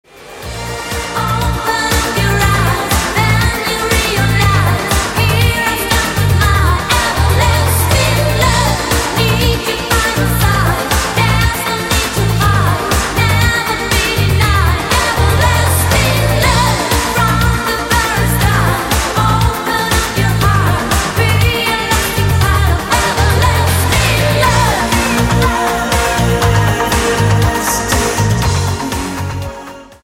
• Качество: 128, Stereo
Synth Pop
disco
ретро
дискотека 80-х